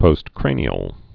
(pōst-krānē-əl)